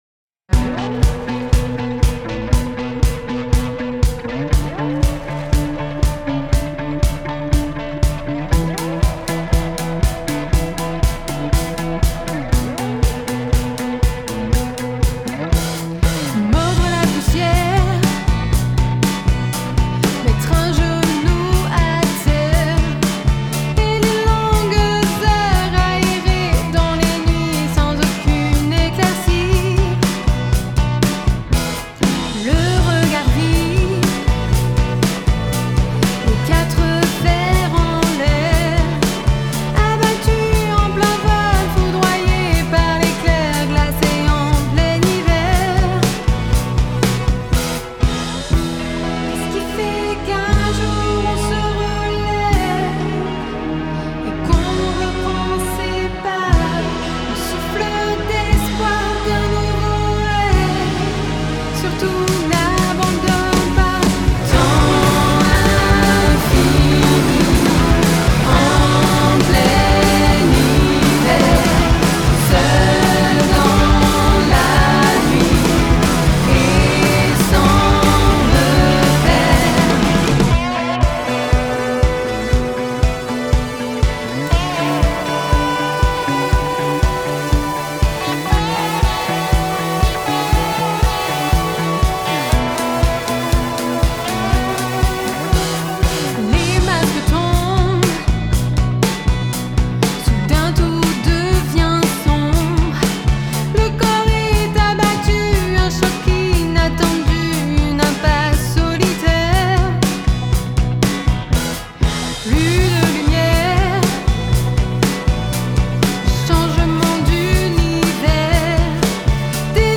Musique NEO-RETRO